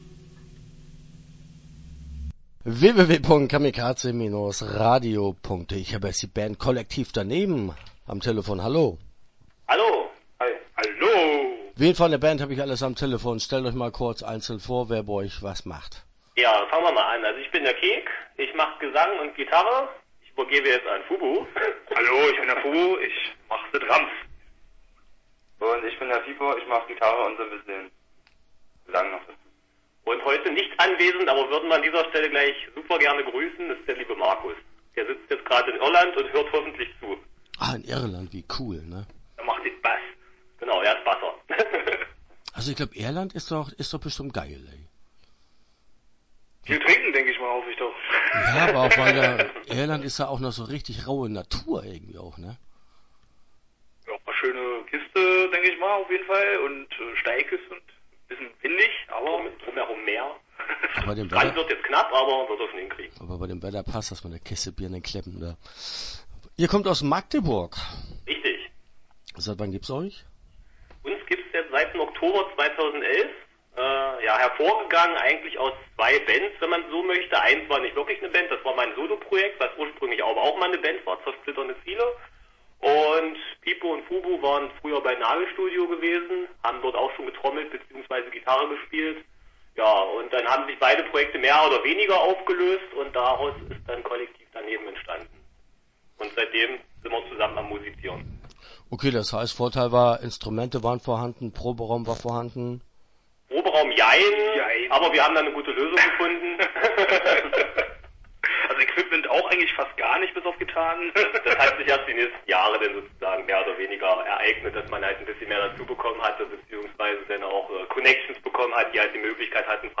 Kollektiv Daneben - Interview Teil 1 (11:03)